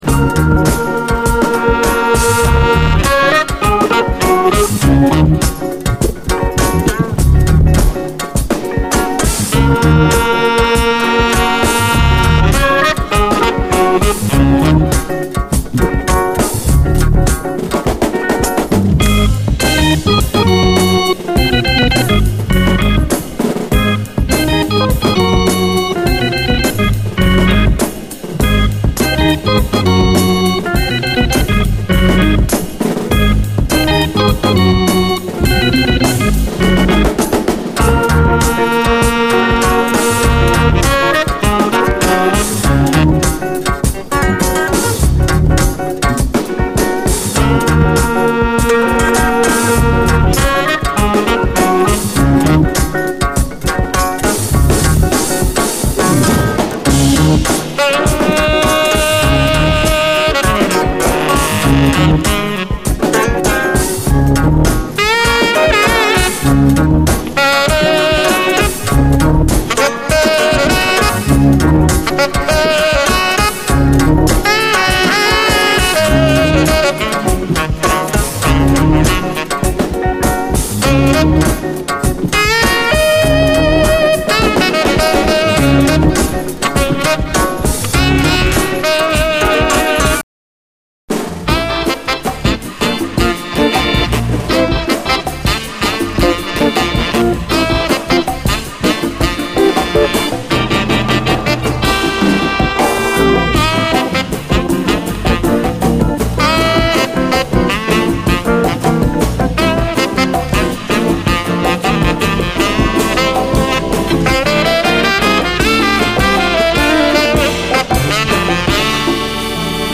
JAZZ FUNK / SOUL JAZZ, JAZZ